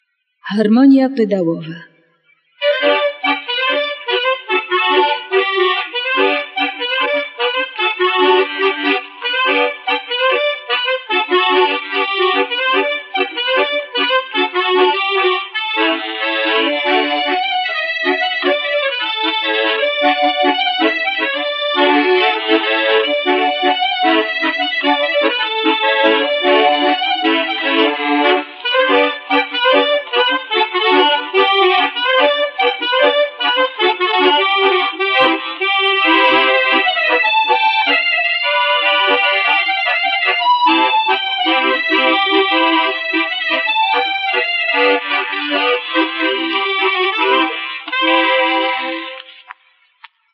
harmonia pedałowa
harmonia_pedalowa.mp3